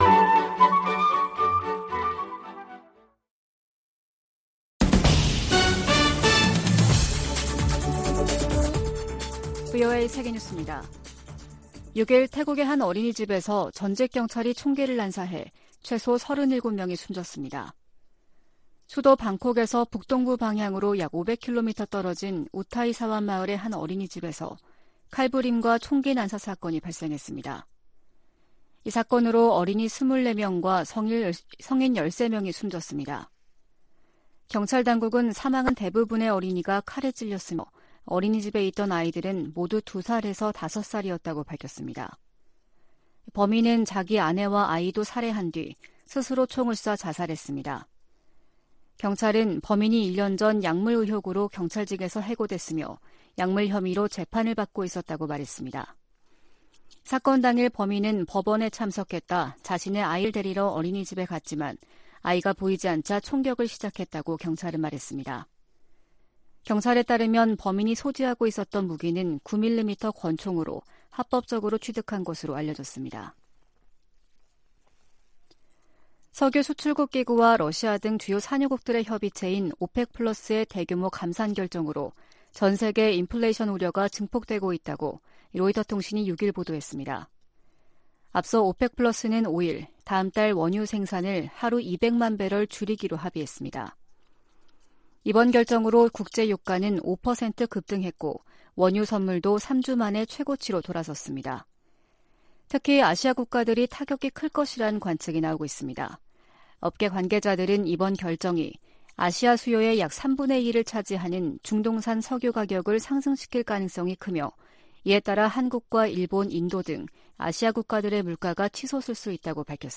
VOA 한국어 아침 뉴스 프로그램 '워싱턴 뉴스 광장' 2022년 10월 7일 방송입니다. 북한이 또 다시 단거리 탄도미사일 두발을 동해상으로 발사했습니다. 유엔 안보리가 북한의 탄도미사일 발사에 대응한 공개회의를 개최하고 북한을 강하게 규탄했습니다.